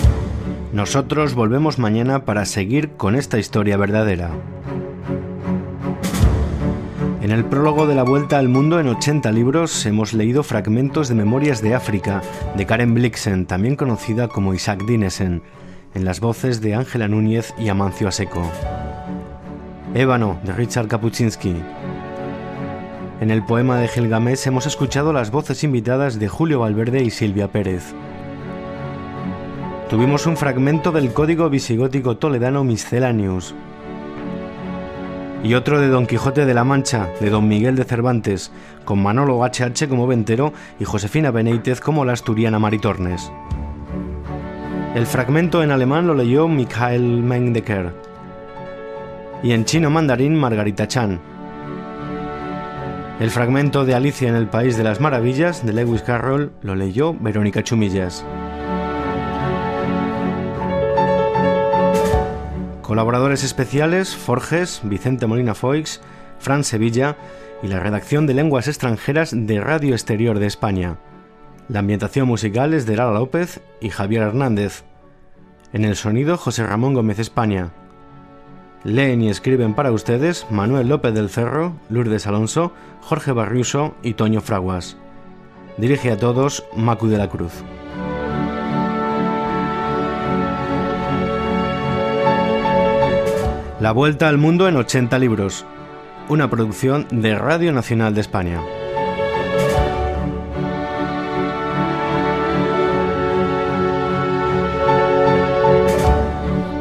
Radio Nacional de España Barcelona